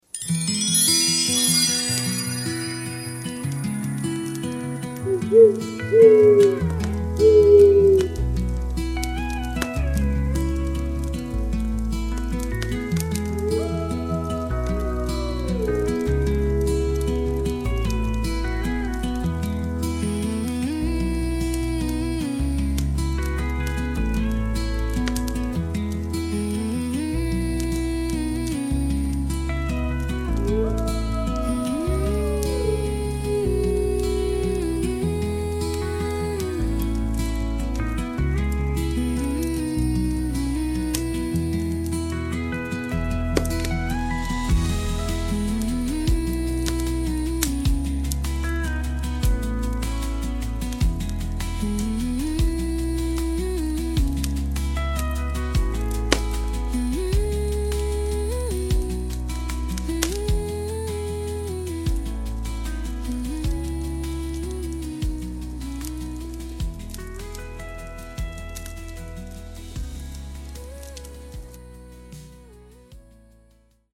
website-background-music-scroll1.mp3